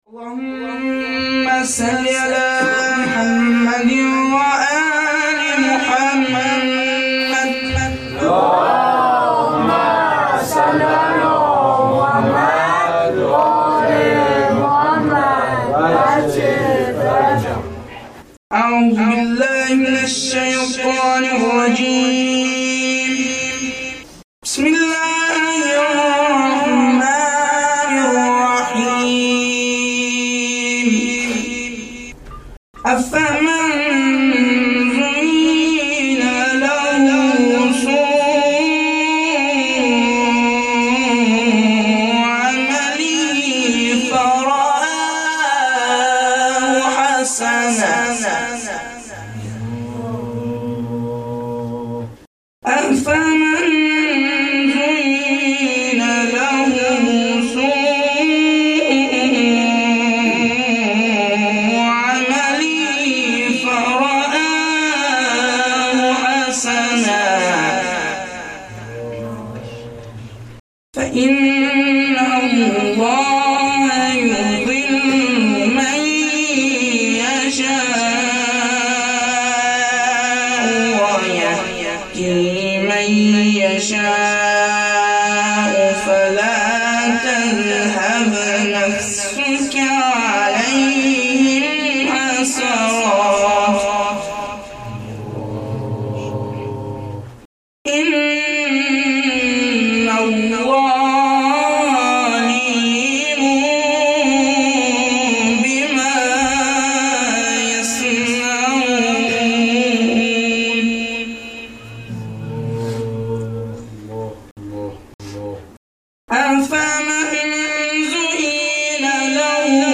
قرائت شب سوم فاطمیه 1393
مداحی فاطمیه